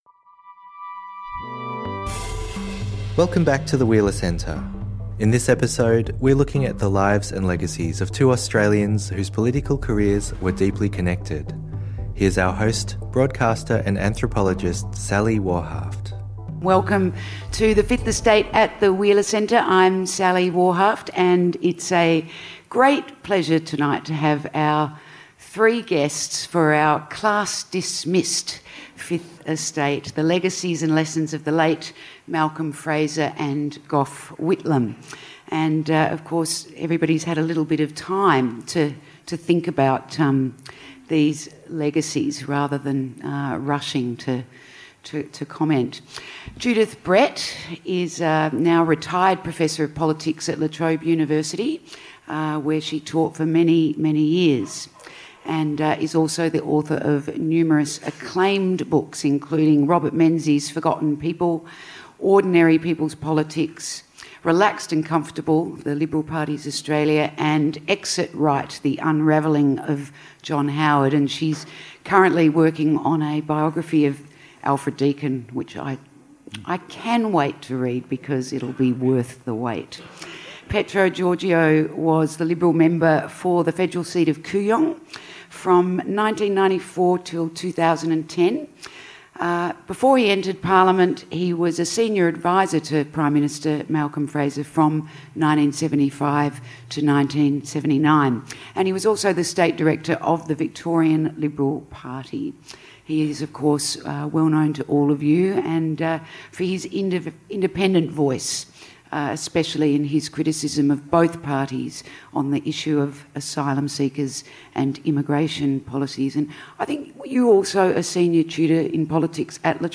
Expect an intimate discussion of the vastly different contributions made by Whitlam and Fraser to the political world, and to Australia as a nation — from free education to immigration to Medicare to arts funding.